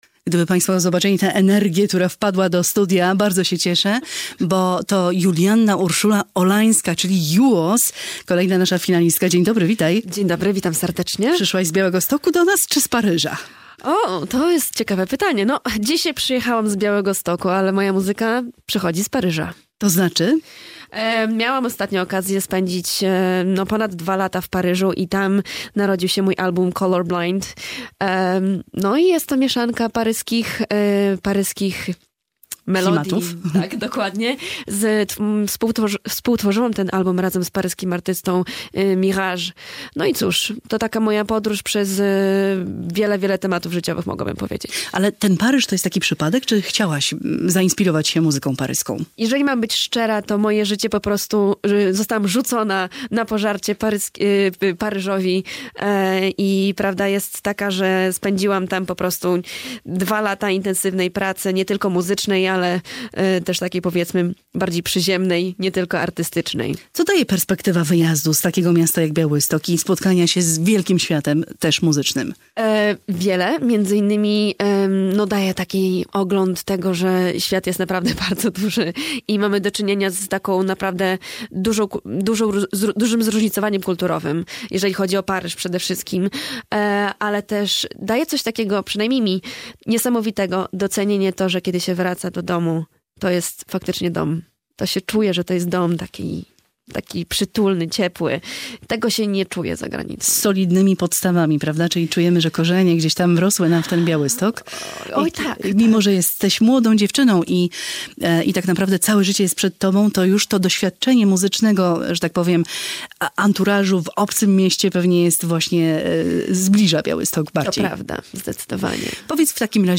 rozmawia